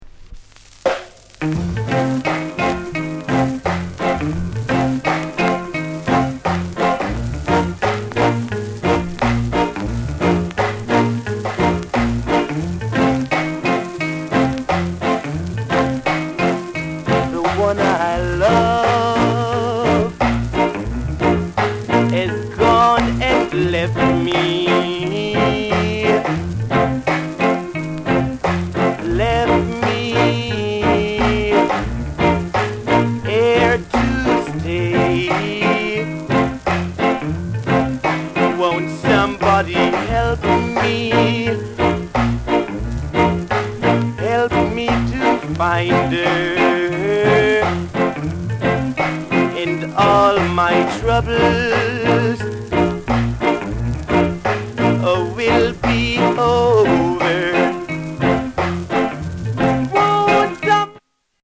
SOUND CONDITION A SIDE VG(OK)
RARE ROKCSTEADY